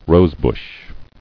[rose·bush]